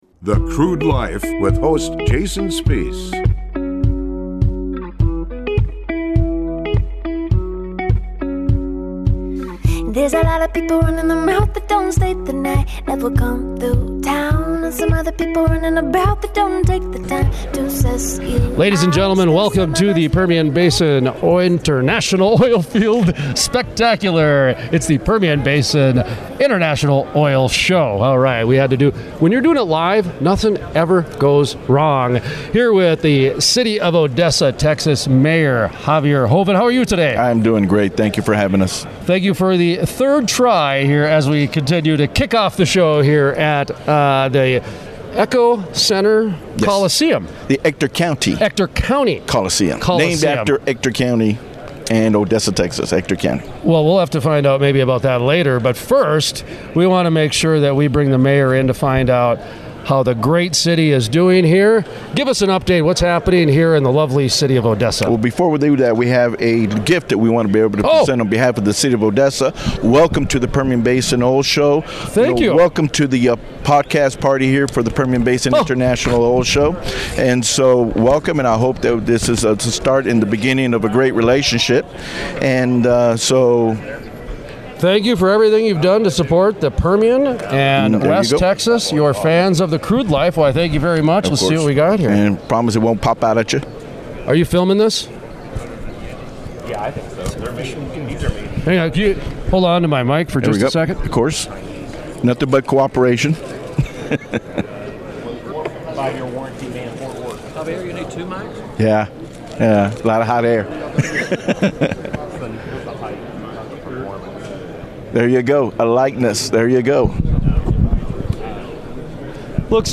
The city of Odessa (TX) Mayor Javier Joven talks about why the city of Odessa is primed for growth in the oil and gas industry during the Permian Podcast Party sponsored by The Wireline Group at the Permian Basin International Oil Show. Texas RailRoad Commissioner Jim Wright gives an update from the Railroad Commissioner’s office and outlook for 2022 during the Podcast Party at the PBIOS.